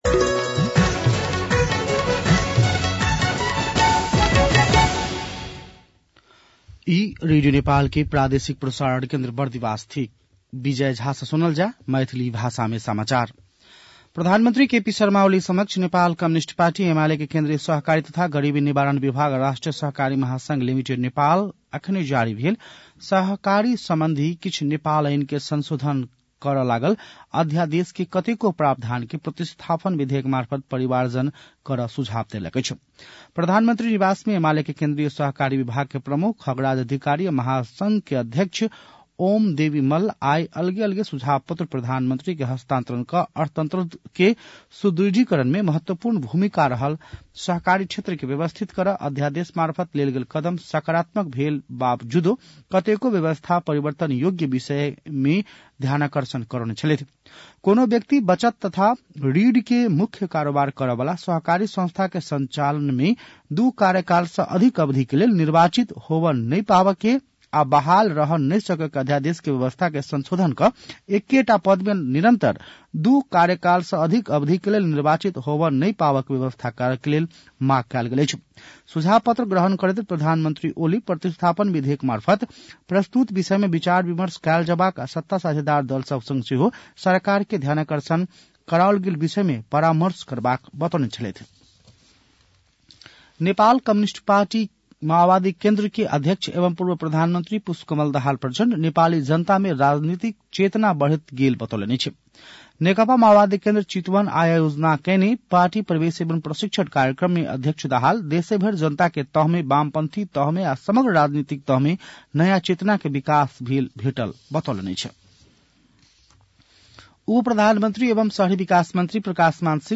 मैथिली भाषामा समाचार : २० माघ , २०८१